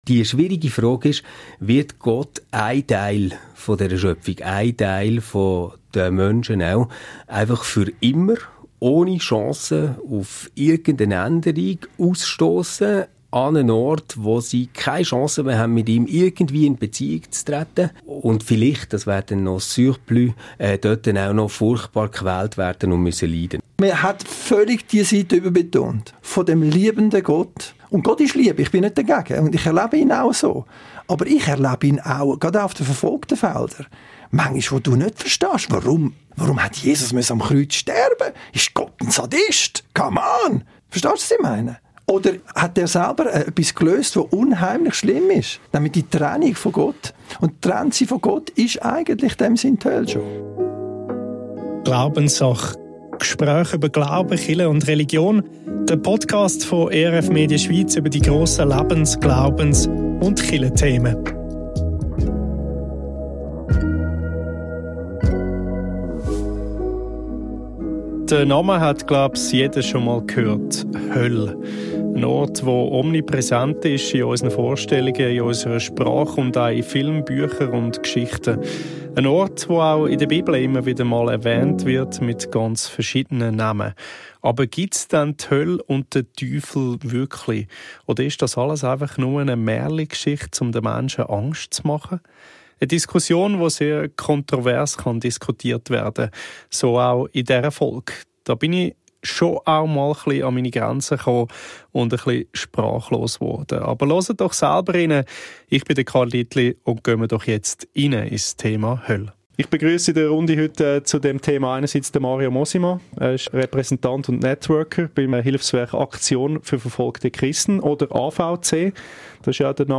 Er hat zwei Gäste mit verschiedenen Ansichten eingeladen.